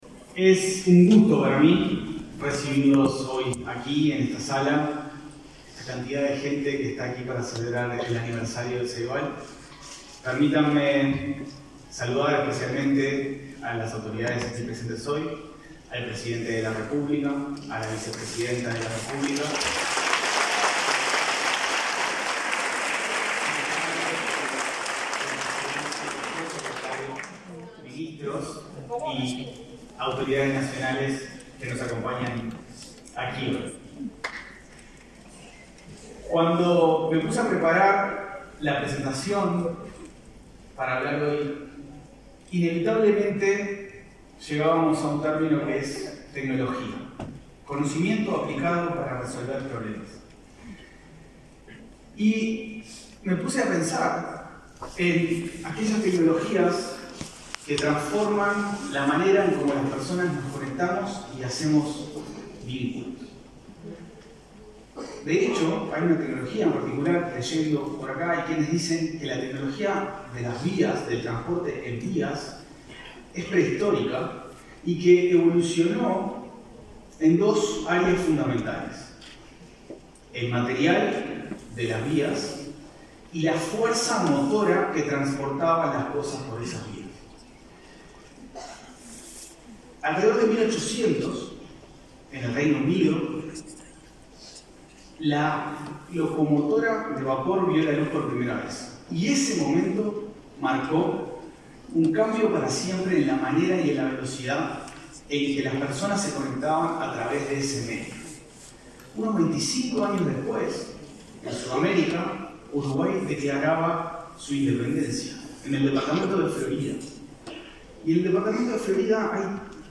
Palabras del presidente de Ceibal, Leandro Folgar
Palabras del presidente de Ceibal, Leandro Folgar 31/05/2022 Compartir Facebook X Copiar enlace WhatsApp LinkedIn Con la presencia de autoridades nacionales, visitas internacionales, estudiantes y docentes, este 30 de mayo, se realizó el evento en el que Ceibal festejó sus primeros 15 años. Disertó el presidente de la institución, Leandro Folgar.
Folgar oratoria.mp3